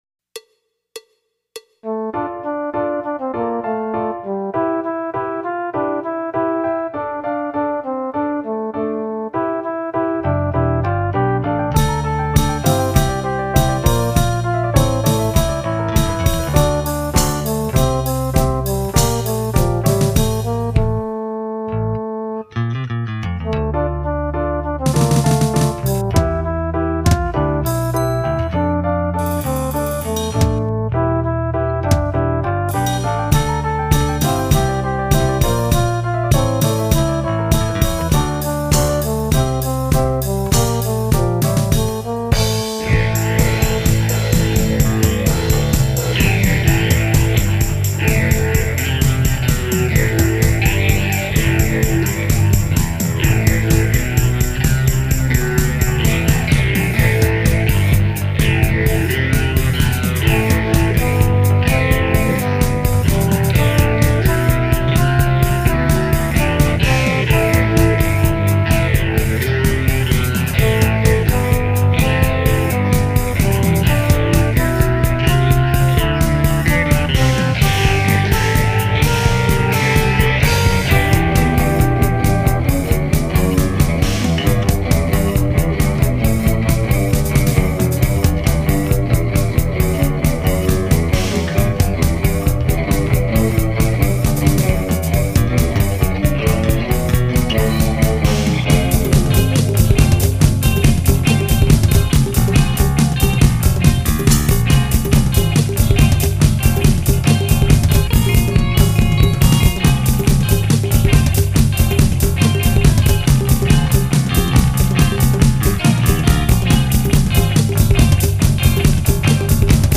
Lead Guitars/
Drums/Percussion